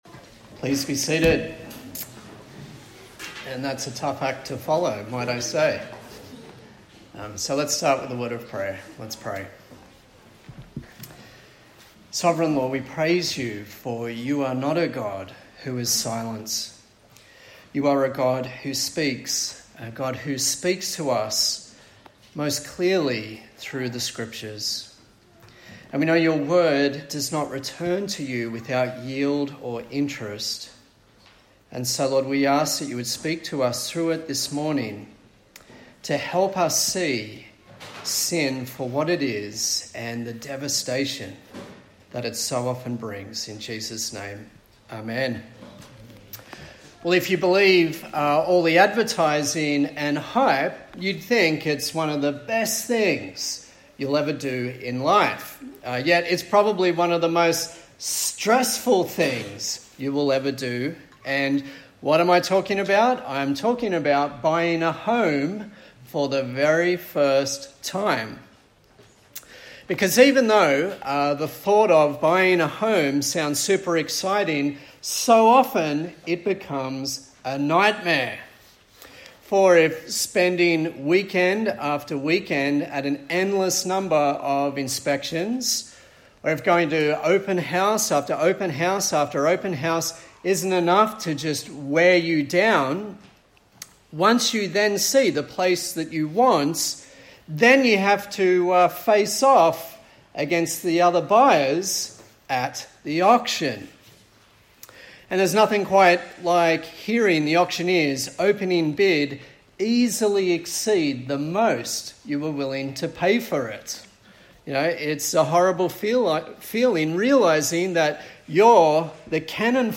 A sermon in the series on the book of Acts
Service Type: Sunday Morning